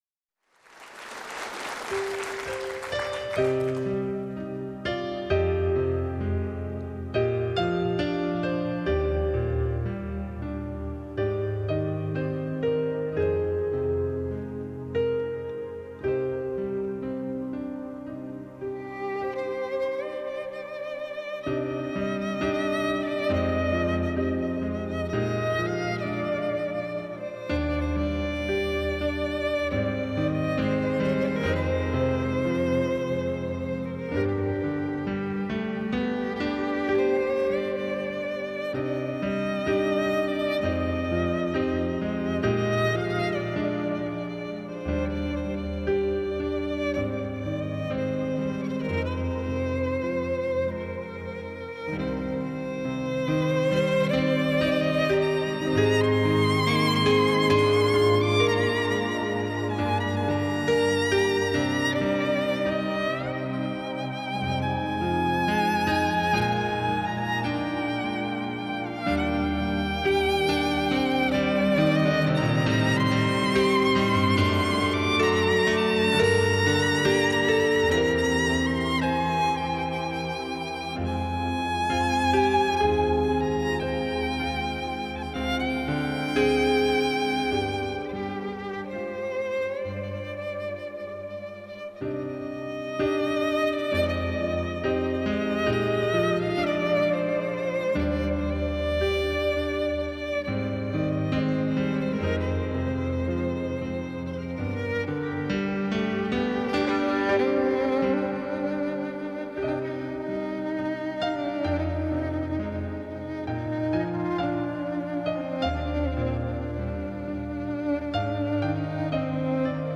CD'TWO Live  Tracks 现场曲目